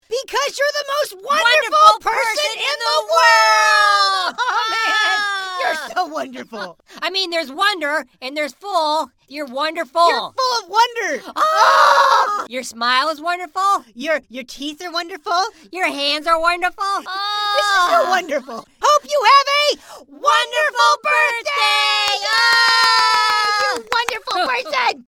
You are Wonderful is a hoops&yoyo greeting card with sound made for birthdays.
Card sound